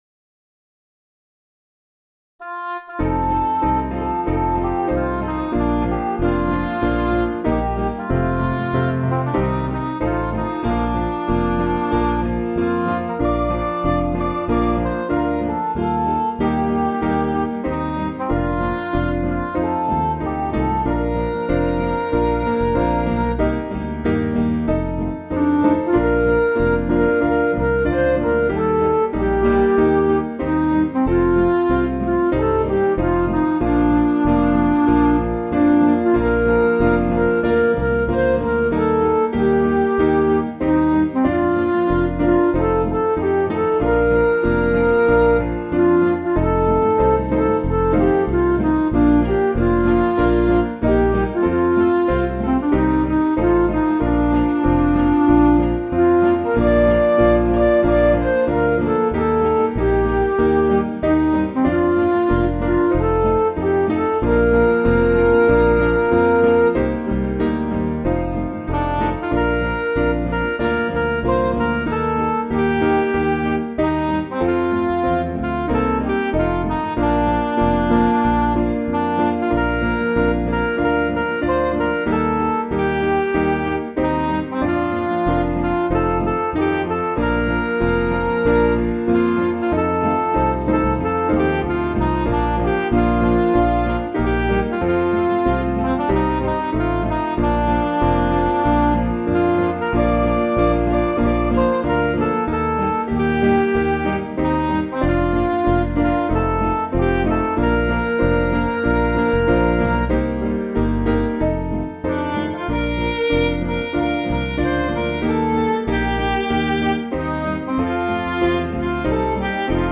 Piano & Instrumental
(CM)   3/Bb